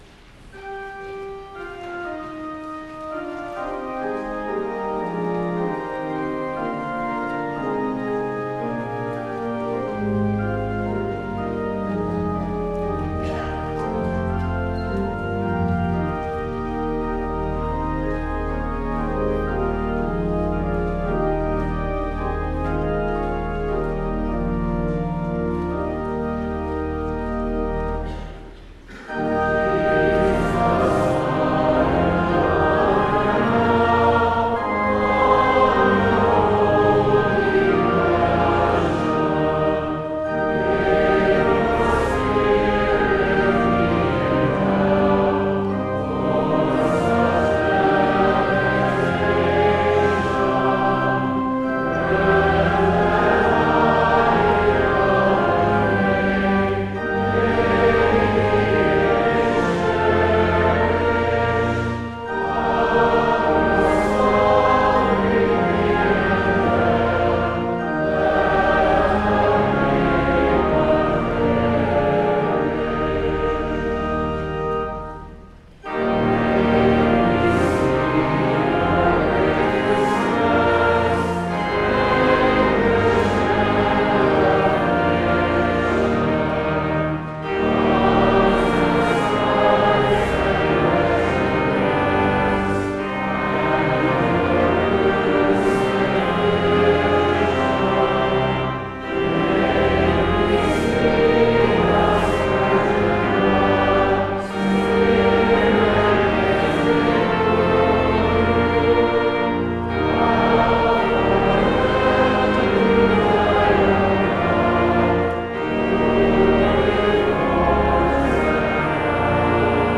Good Friday, March 30, 2018
clarinet